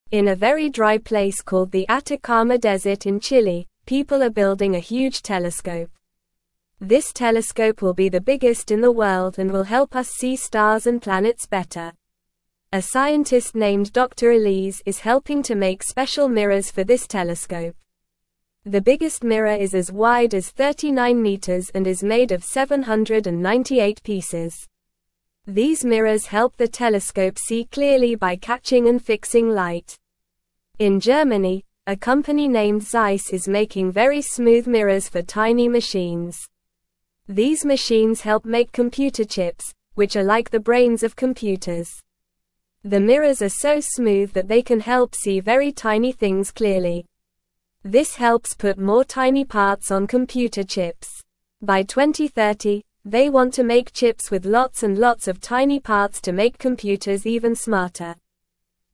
Normal
English-Newsroom-Beginner-NORMAL-Reading-Building-a-Big-Telescope-and-Making-Smooth-Mirrors.mp3